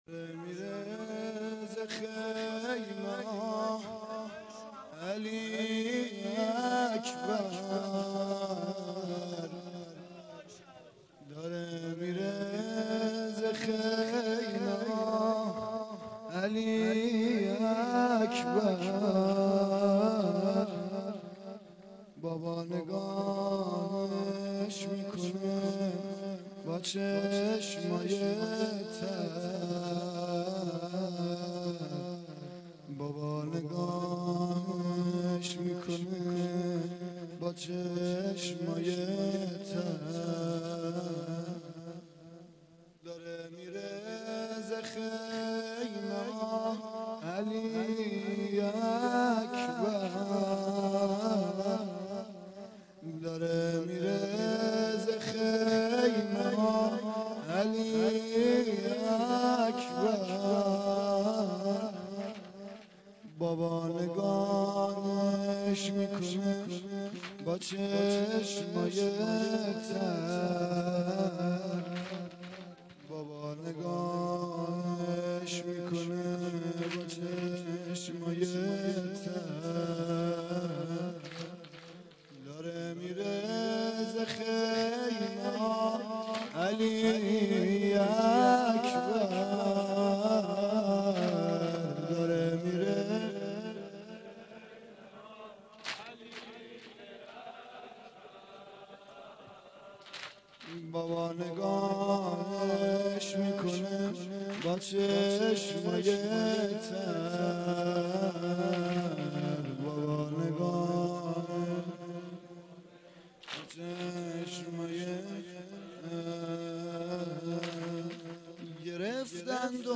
واحد شب هشتم محرم